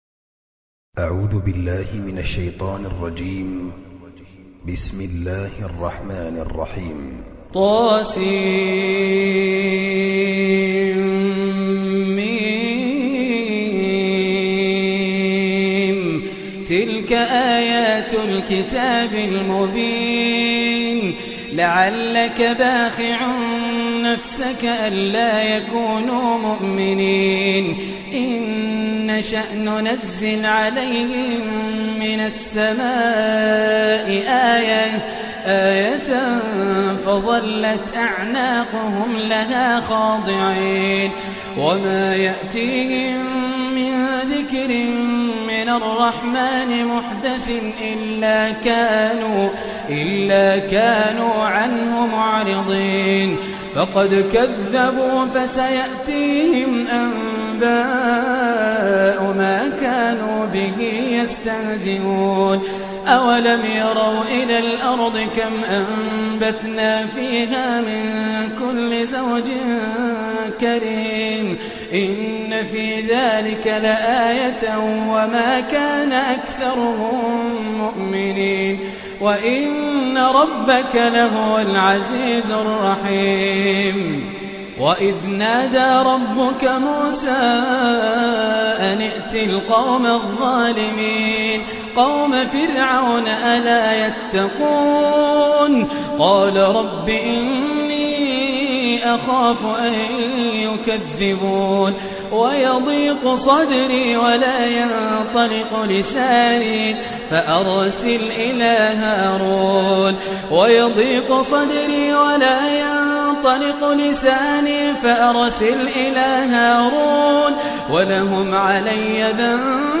(Riwayat Hafs)